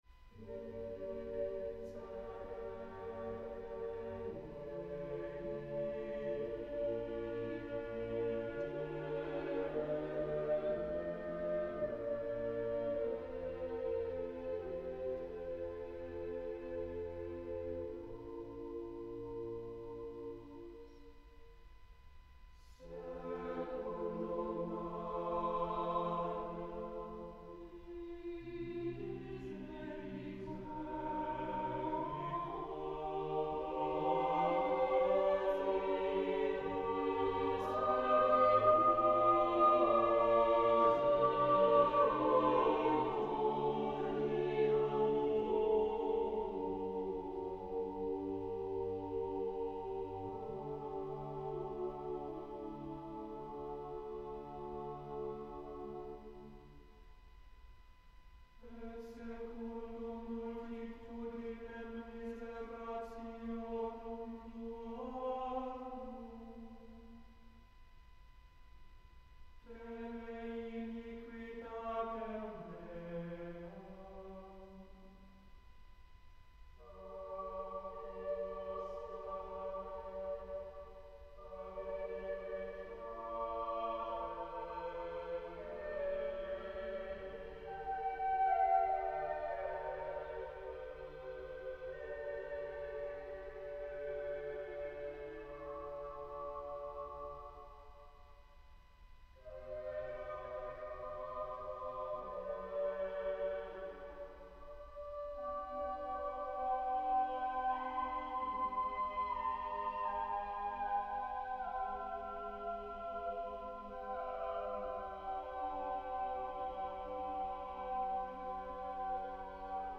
1. The chanting. Inserted between each verse, it gives the piece a middle-agey feel, despite the fact that it was written during the Baroque period.
Although the soprano solo with its top C is exciting, each vocal line is beautiful in its own way. I wouldn’t use “haunting” or “mysterious” to describe many songs, but this one fits.
Tags1600s baroque Choral classical Europe-Asia